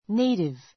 native 中 A2 néitiv ネ イティ ヴ 形容詞 ❶ 故郷の, 生まれた my native country [land] my native country [land] 私の生まれた国, 故国 French is her native language.